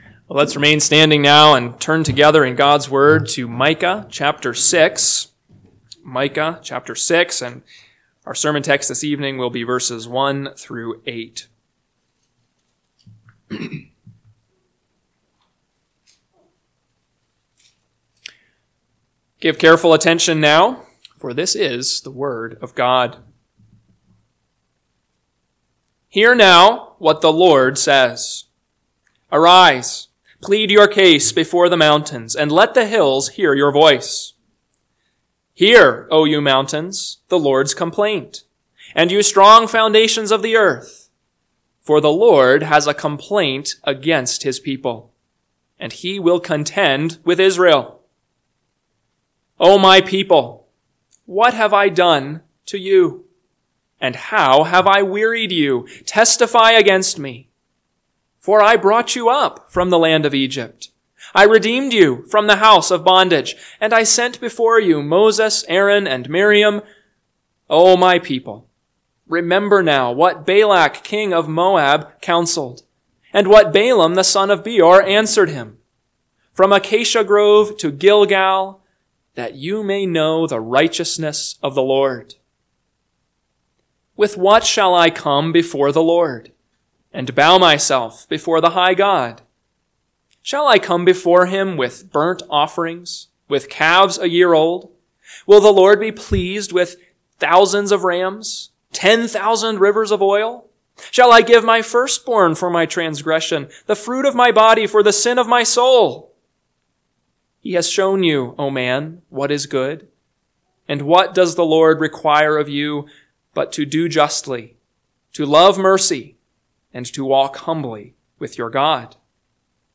PM Sermon – 11/17/2024 – Micah 6:1-8 – Northwoods Sermons